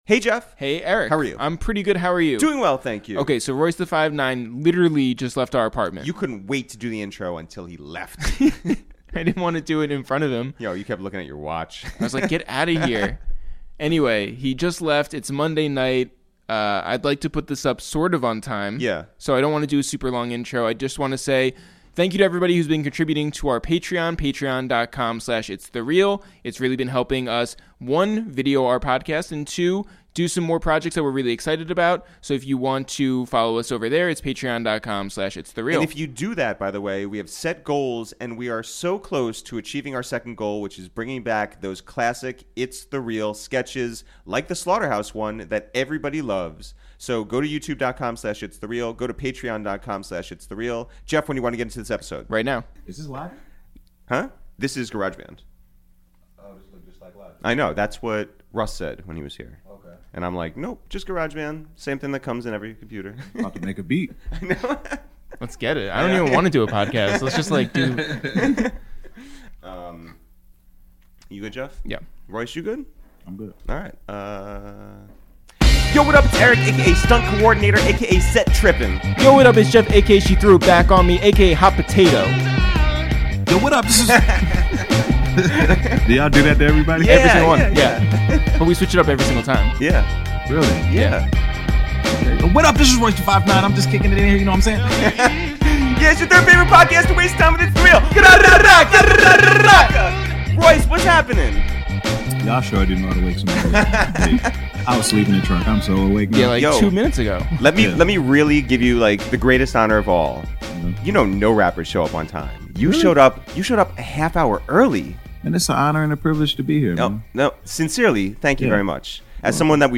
This week on A Waste of Time with ItsTheReal, we welcome Detroit's ownRoyce da 5'9 back to the Upper West Side for a deep, hilarious, revealing, and very meaningful conversation on what he misses about Slaughterhouse, their unreleased project, the places he and Eminem listen to music, meeting Beyonce for the first time in celebration of Carmen: a Hip-Hopera, listening to 4:44 for the first time alongside Jay Electronica, what he fights with DJ Premier about, doing the Tootsie Roll in a high school talent show, how he broke the cycle when it came to parenting, and much more!